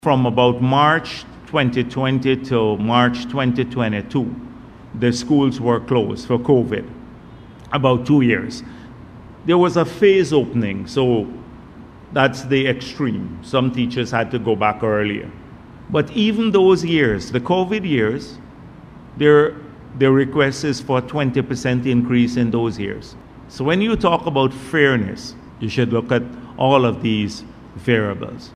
During a press conference, Dr. Bharrat Jagdeo addressed the GTU’s request for a salary hike, noting that the union had sought a 20 percent increase during the pandemic period when teachers were not required to be in schools.